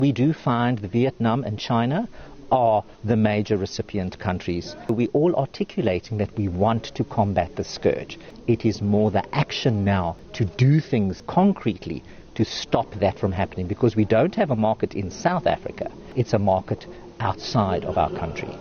George het aan SABC News gesê tegnologie-gedrewe veldtogte sal help om Suid-Afrika se renoster-nalatenskap te beskerm.